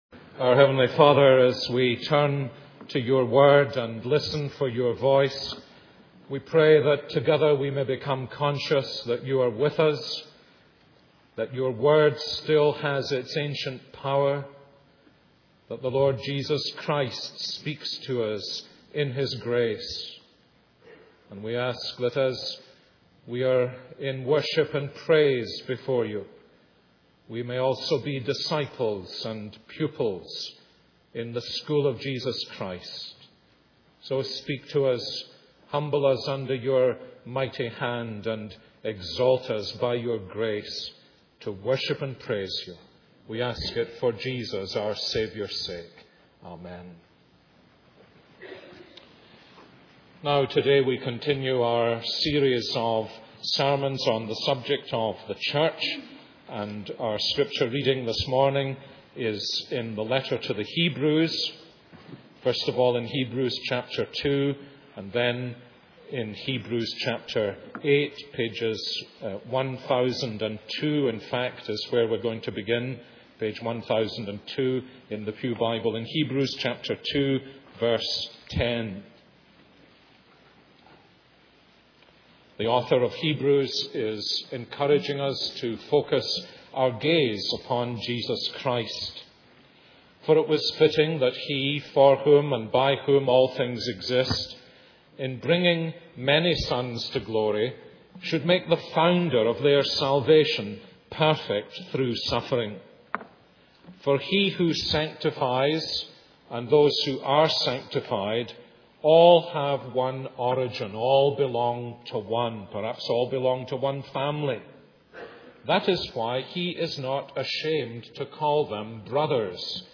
The Church’s Worship The Life of the Church Series: Sermon Three